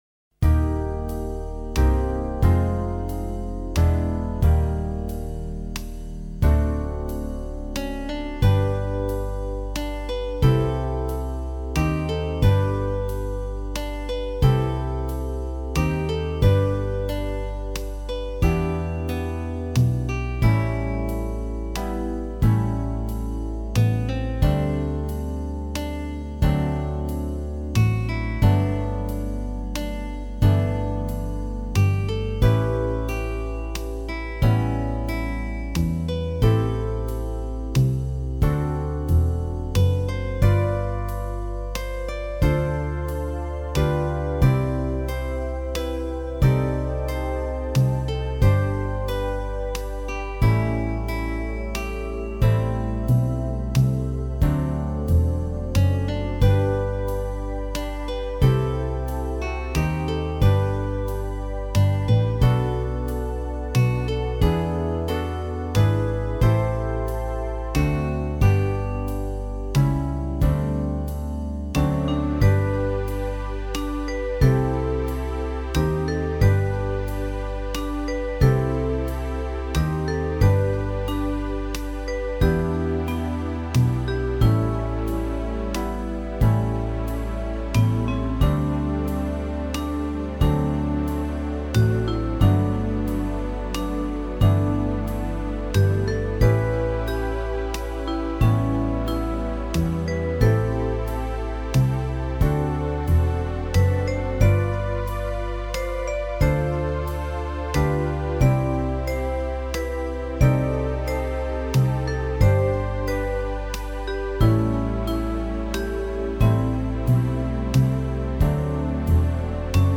Enkla taktfasta